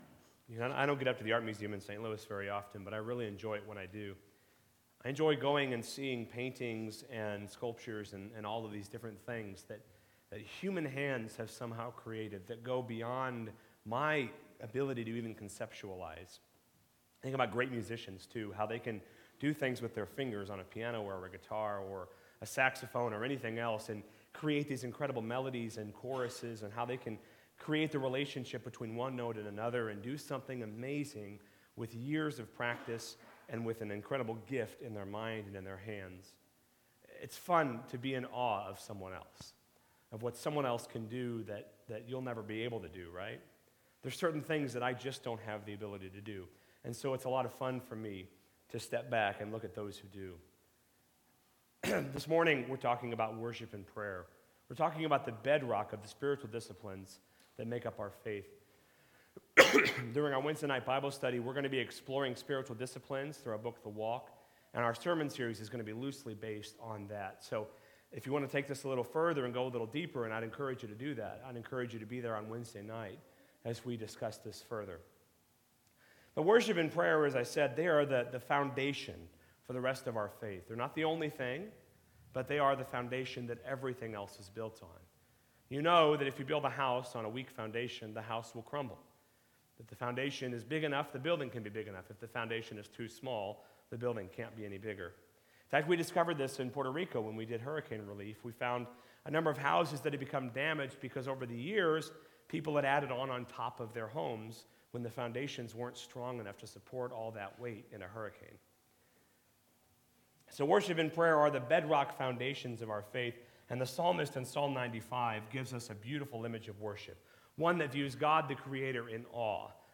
Passage: Psalm 95 Service Type: Sunday Morning Topics